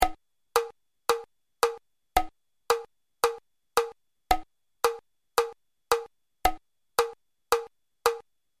Für die Hörbeispiele habe ich einen einfachen 4/4 Takt gewählt.
moderato
mäßig schnell
BPM: 112